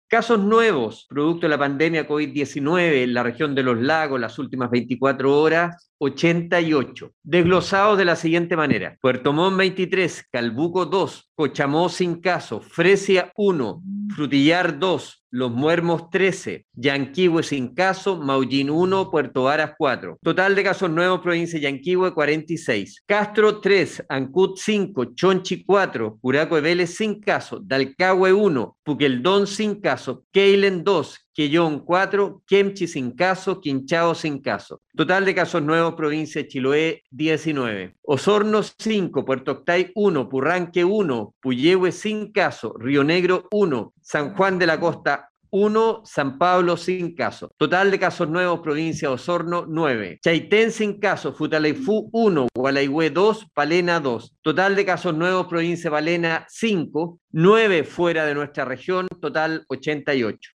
El mediodía de este lunes, el Seremi de Salud Alejandro Caroca, entregó el reporte de nuevos contagios por Covid-19 en la región de Los Lagos, con corte al día 18 de julio, a las 18 hrs: